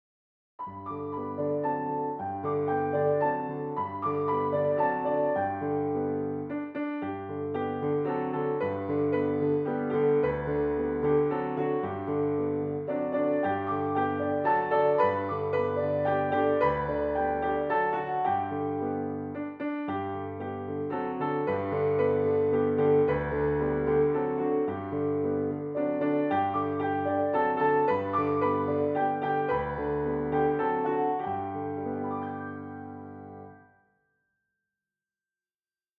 melodija: serbski ludowy spiw